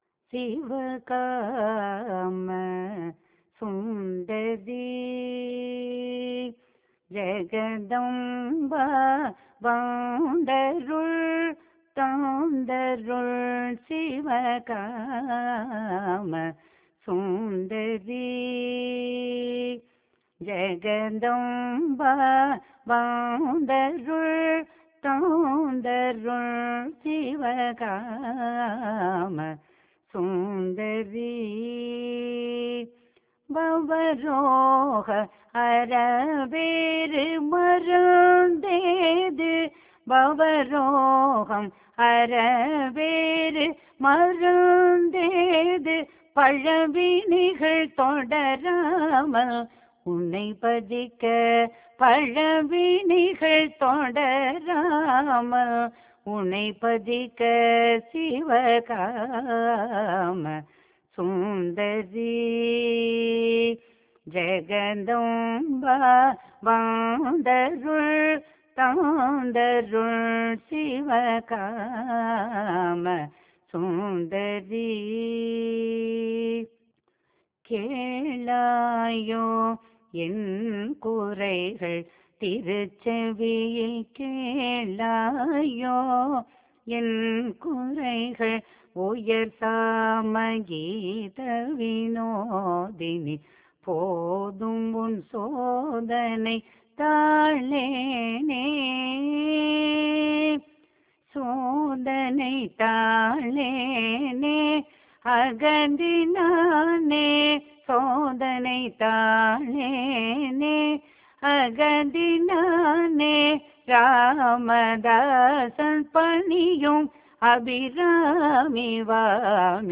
இராகம் : தோடி தாளம் : ஆதி